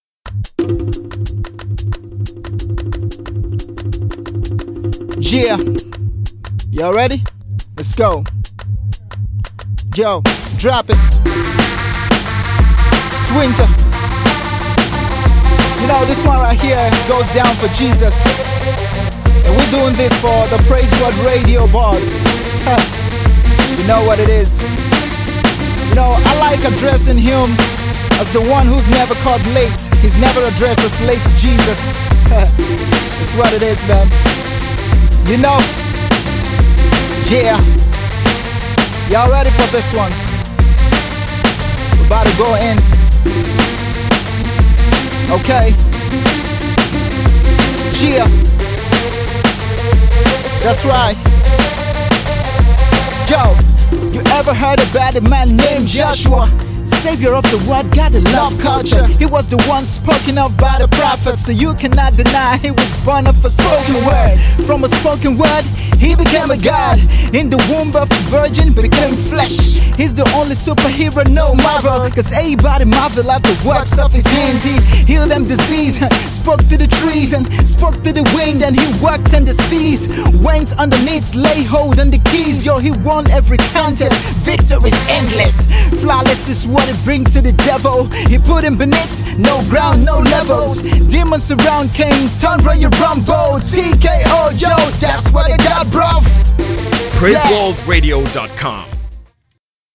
Young Nigerian rapper